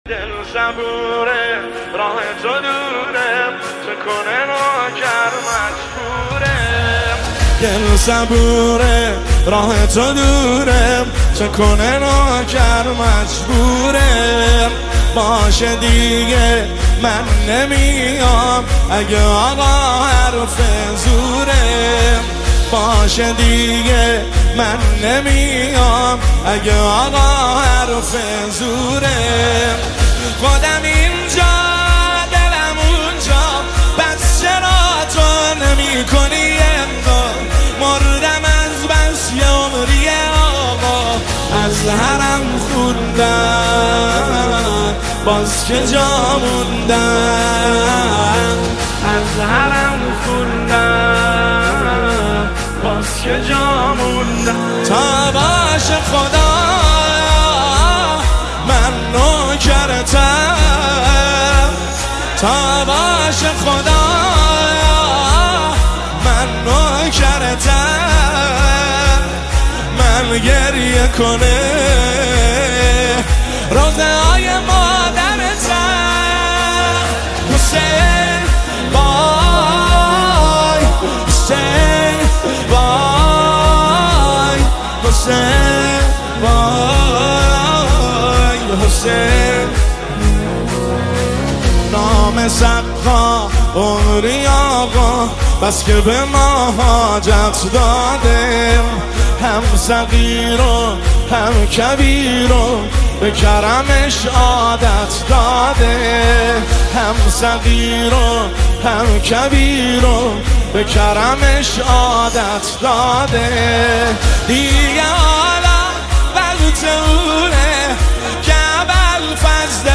نماهنگ مذهبی مداحی مذهبی